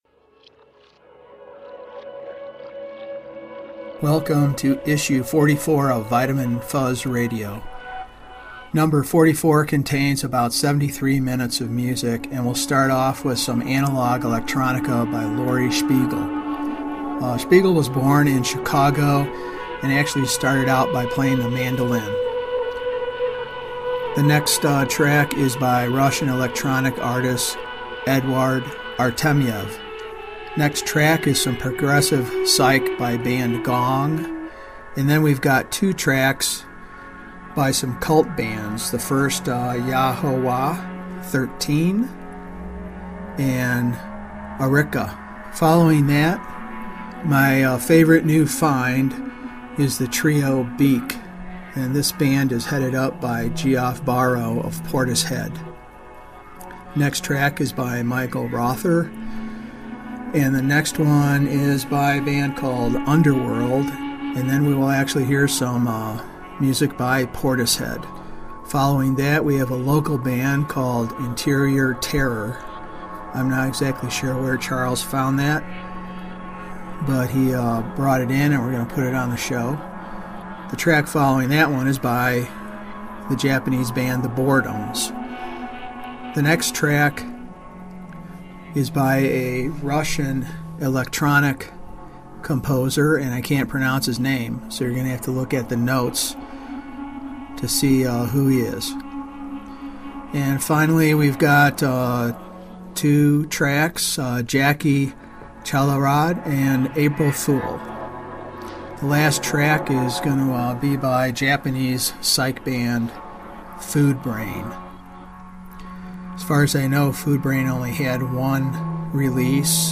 Vitamin Fuzz Radio Collective has been transporting you toward unexplored Psychedelic skylines since 2008 with broadcasts via our PODCAST or mp3 downloads.